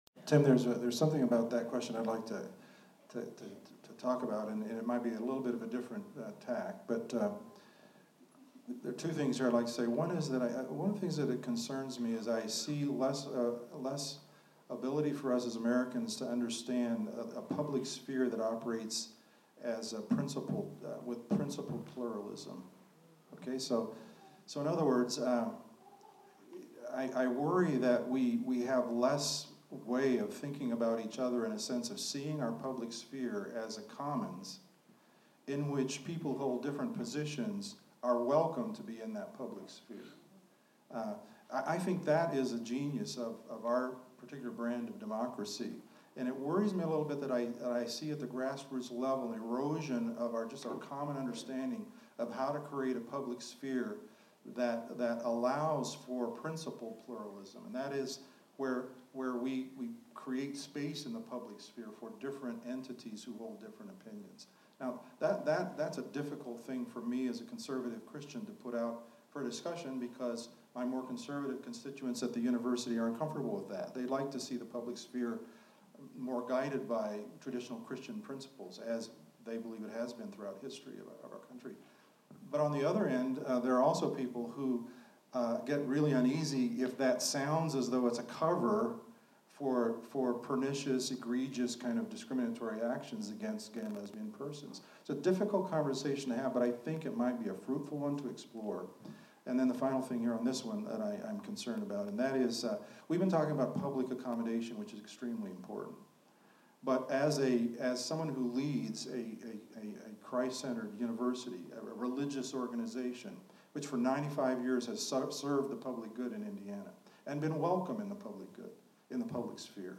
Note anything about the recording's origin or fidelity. speaks out at an Indianapolis Star forum on religious freedom issues in Indiana and their potential impact on the service of religious schools.